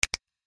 Samsung Galaxy Bildirim Sesleri - Dijital Eşik
click.mp3